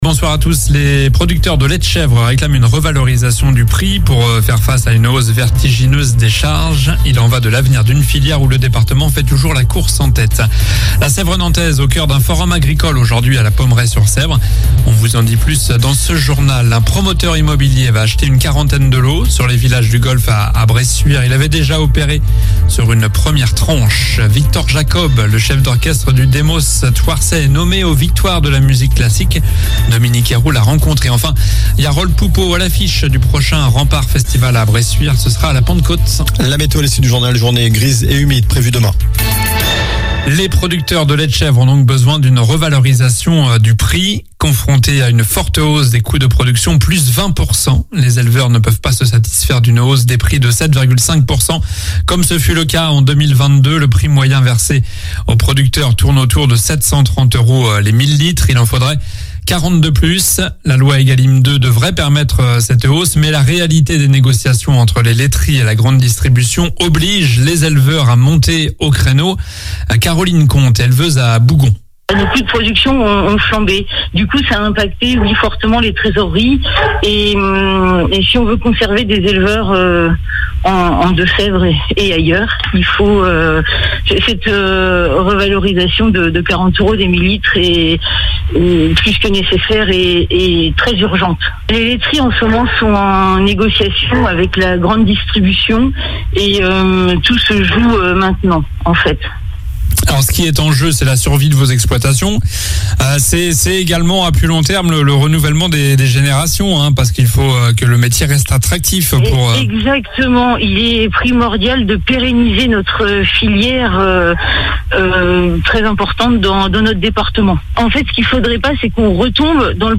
Journal du mardi 21 février (soir)